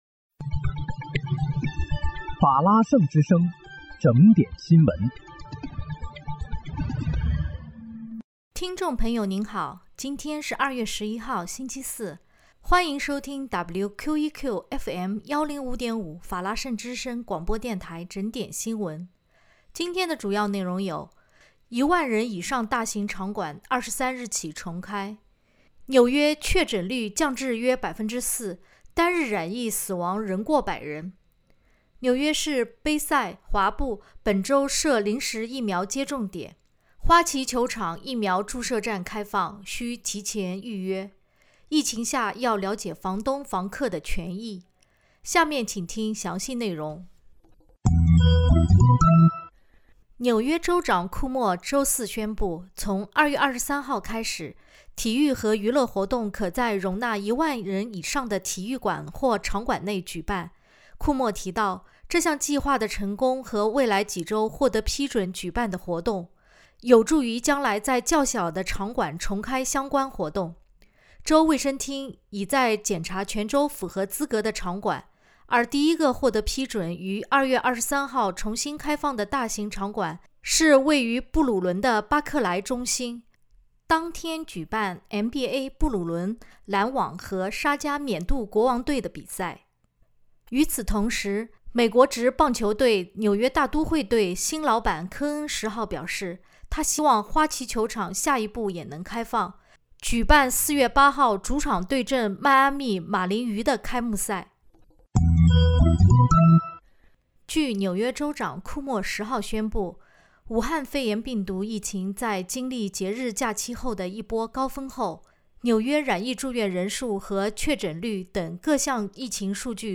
2月11日（星期四）纽约整点新闻
听众朋友您好！今天是2月11号，星期四，欢迎收听WQEQFM105.5法拉盛之声广播电台整点新闻。